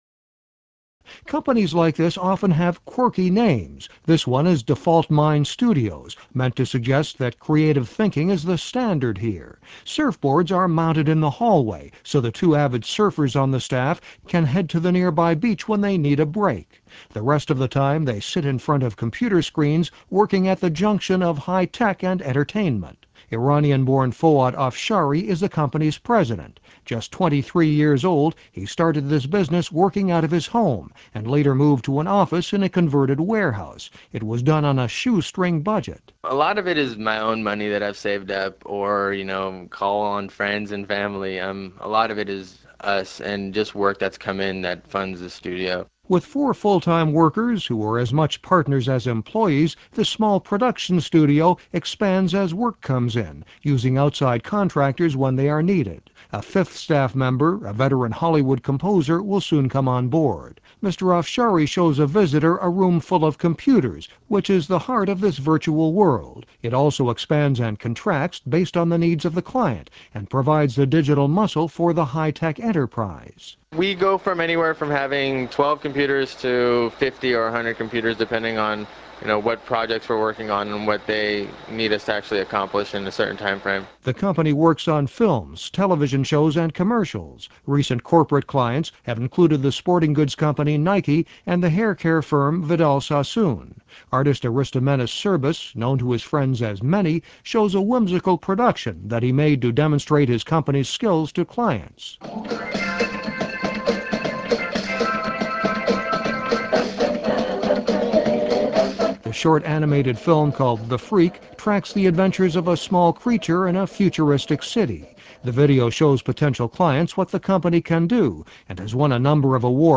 VOA interview with